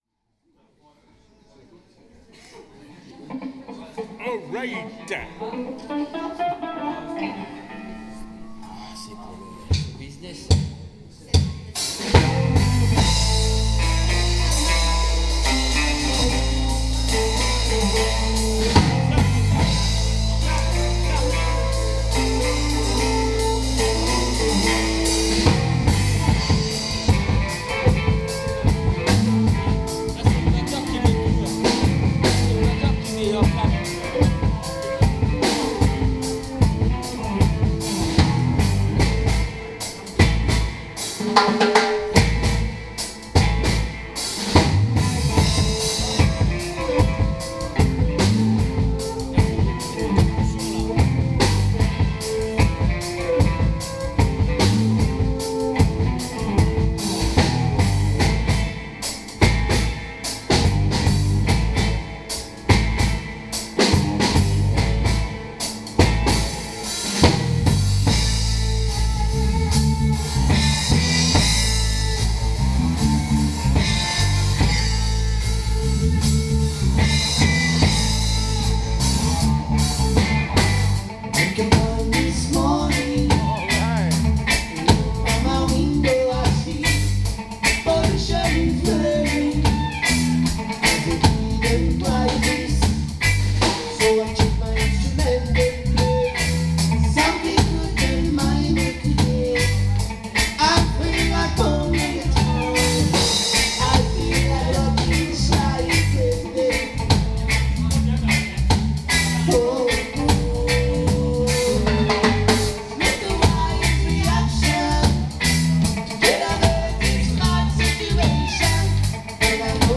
Reggae St. Etienne